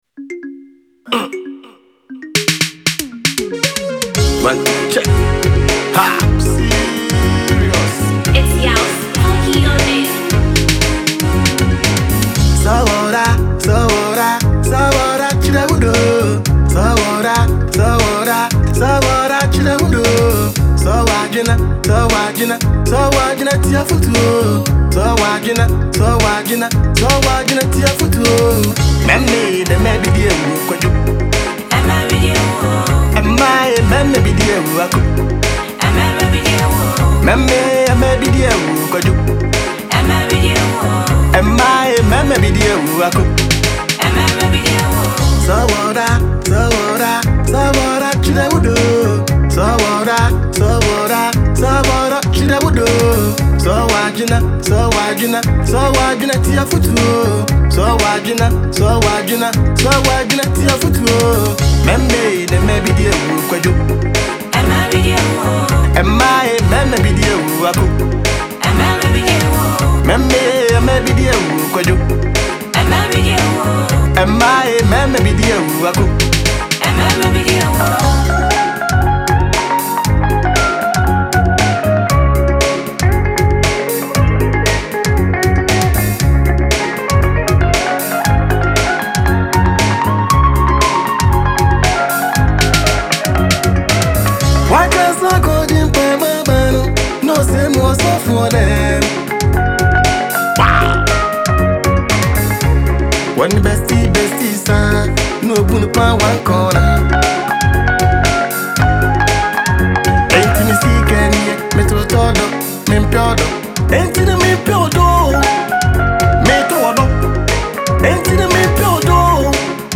The song is a masterful blend of old-school highlife
smooth vocals
energetic delivery